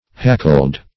Hackle \Hac"kle\, v. t. [imp. & p. p. Hackled (h[a^]k"k'ld);